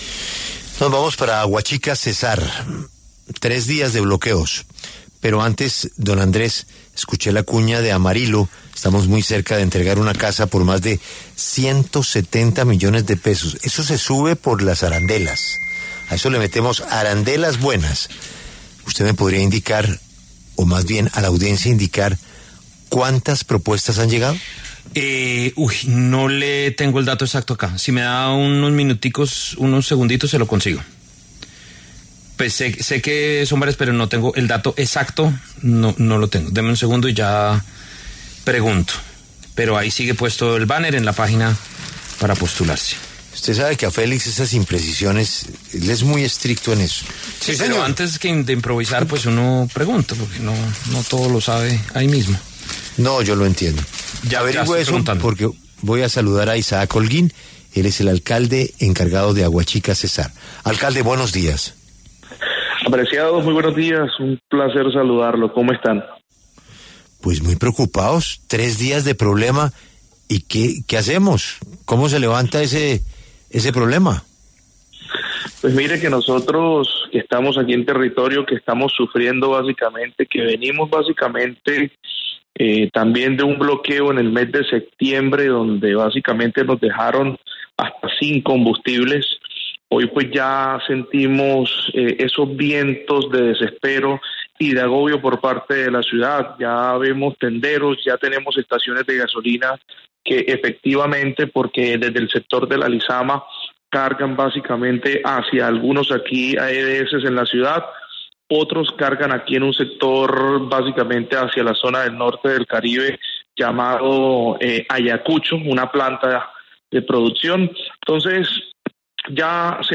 En diálogo con La W, el alcalde encargado de Aguachica, Isaac Holguín, señaló que hay estaciones de gasolina empiezan a presentar desabastecimiento y tiendas empiezan a presentar falta de productos de la canasta familiar que no han podido llegar hasta el municipio.